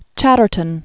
(chătər-tən), Thomas 1752-1770.